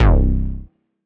Sub Bass - Ritter - C Slide Down.wav